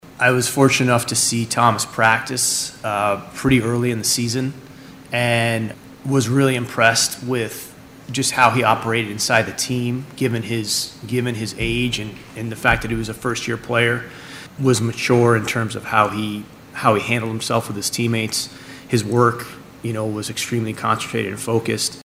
the Thunder held a press conference to introduce its two draft picks following last week’s NBA Draft.